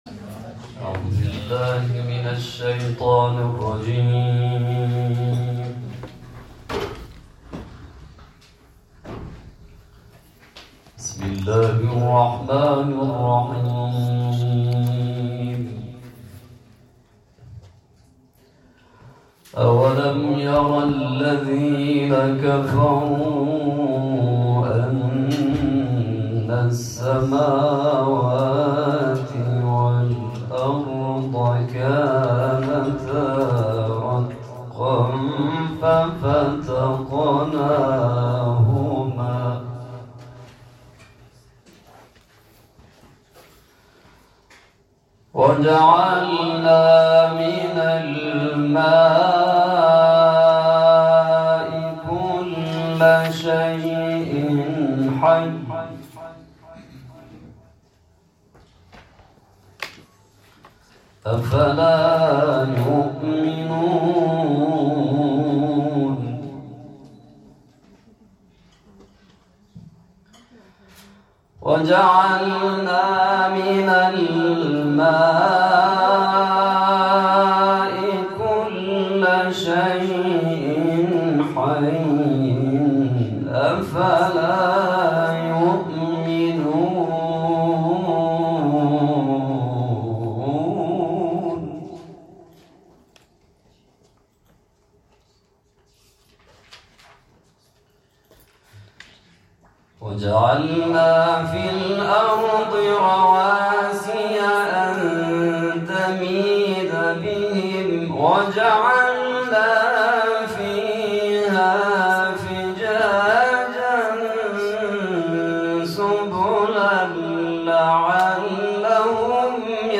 در مراسم رونمایی از پوستر جشنواره ملی «سپاس آب» با حضور مسئولان کشوری و استانی قرائت شده است
تلاوت قرآن